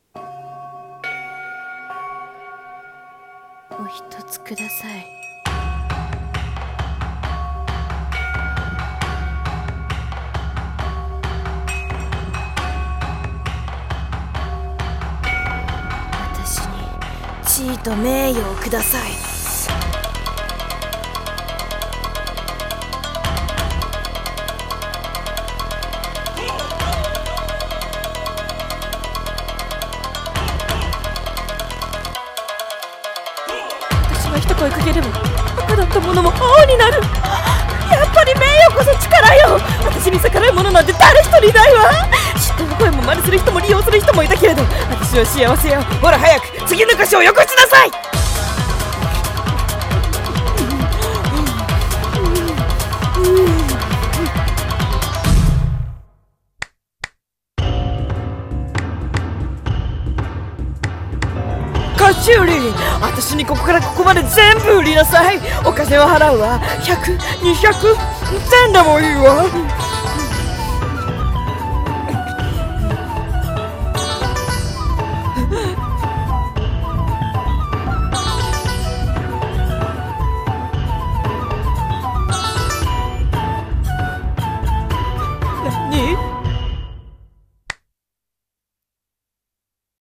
CM風声劇「妖町の菓子売」